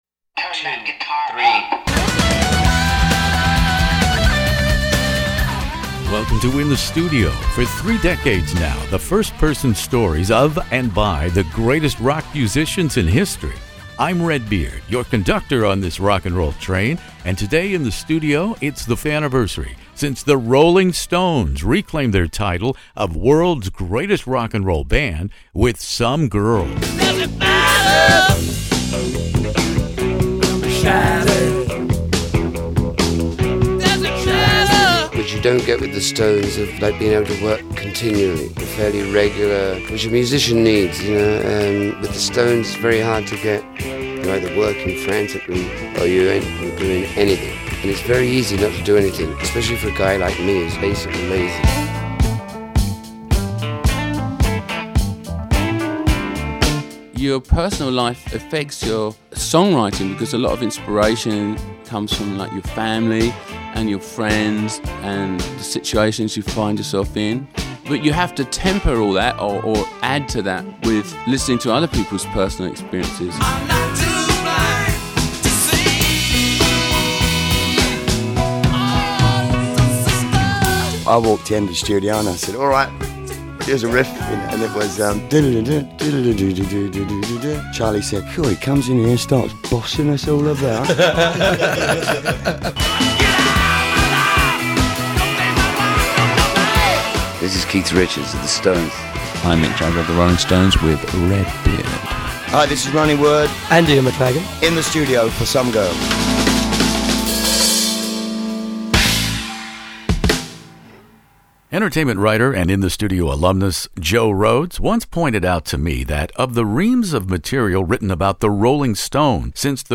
Keith Richards is joined in my classic rock interviews by Mick Jagger, Ronnie Wood, and former Faces keyboard player the late Ian McLagan who played on this Rolling Stones #1 Billboard album and single (“Miss You”).